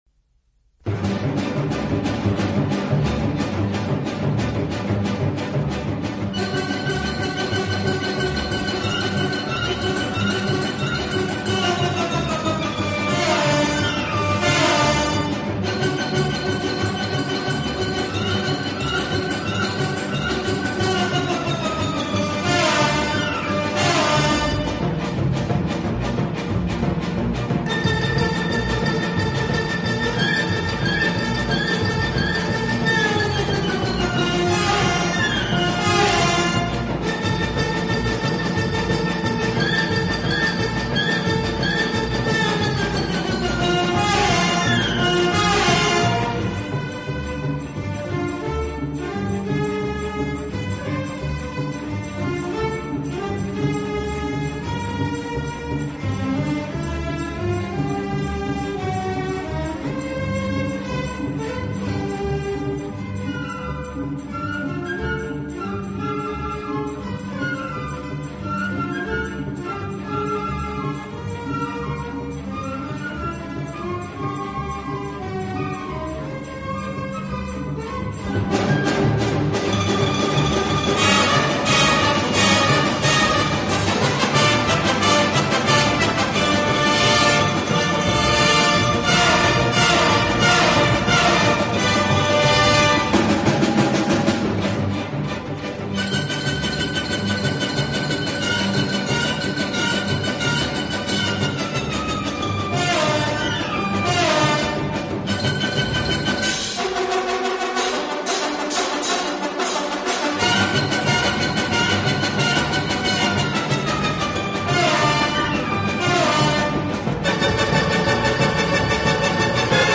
difficile de résister à l’énergie de ces deux morceaux !